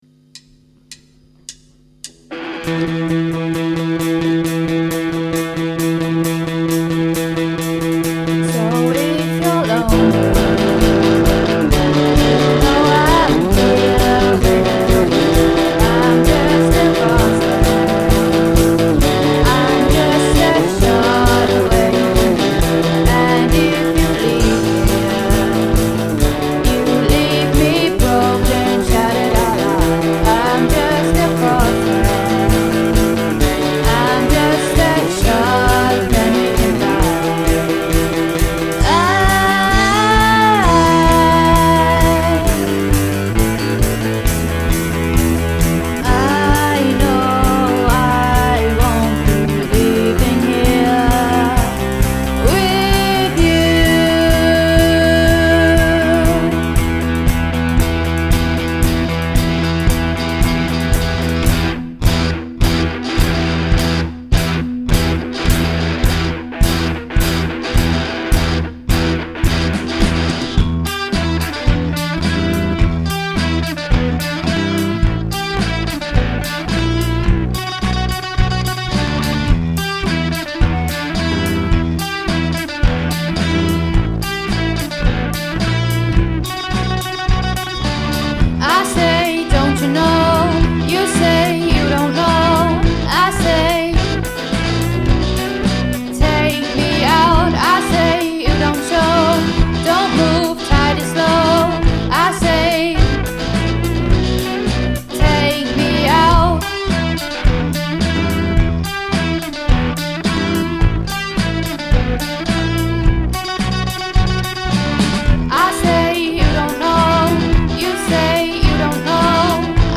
Take Me out - Rock School May 2017